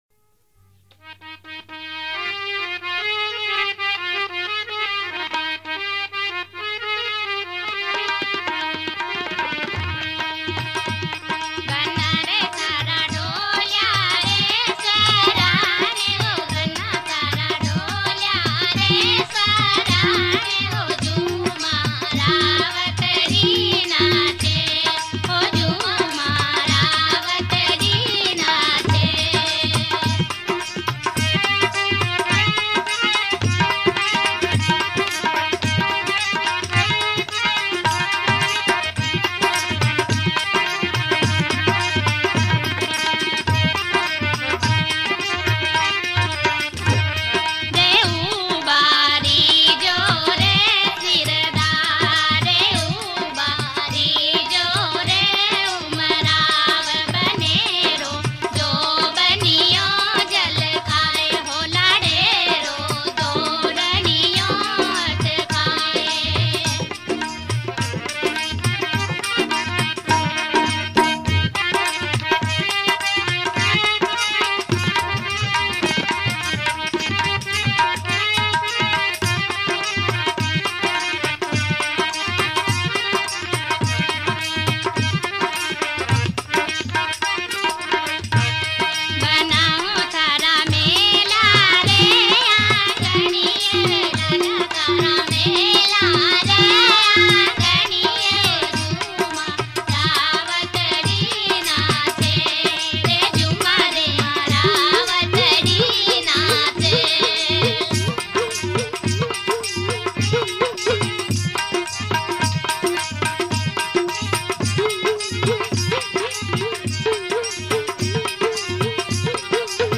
Rajasthani Songs
Banna Banni Geet